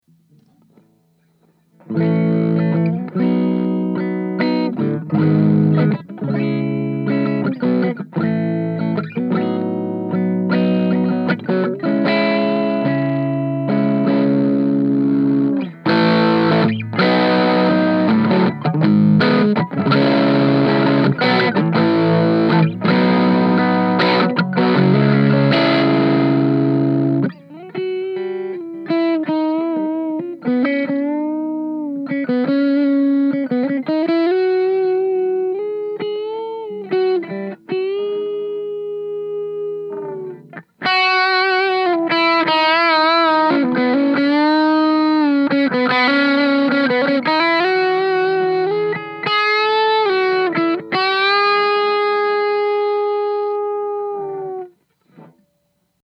It’s mostly transparent, but it does have a bit of a darker color to it.
The first clip, I wanted to demonstrate the response to volume knob adjustments. The first part is my amp with my Les Paul in the middle position with both volume knobs at 5. In the second part, I switch on the pedal, and you can hear how well the pedal’s breakup blends with the amp breakup. In the final part, I do a simple lead line with the pedal engaged, then crank up my bridge pickup. The pedal really responds!